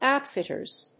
/ˈaʊˌtfɪtɝz(米国英語), ˈaʊˌtfɪtɜ:z(英国英語)/